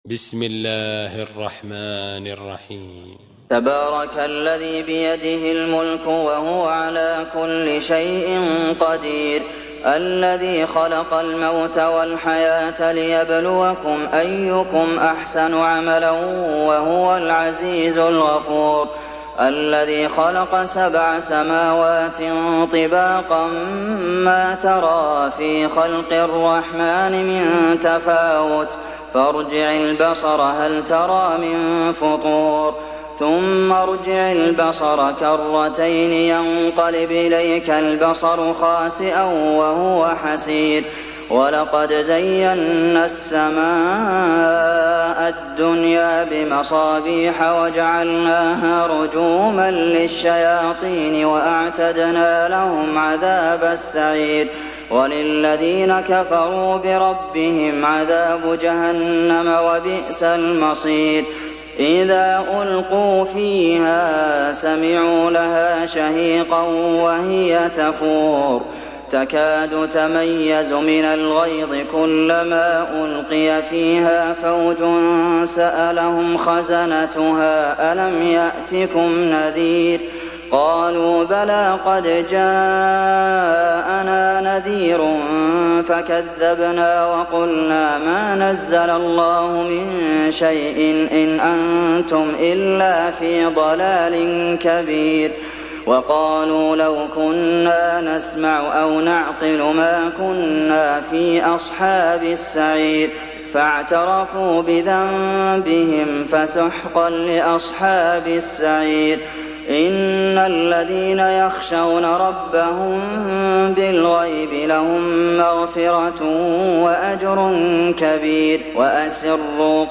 دانلود سوره الملك mp3 عبد المحسن القاسم روایت حفص از عاصم, قرآن را دانلود کنید و گوش کن mp3 ، لینک مستقیم کامل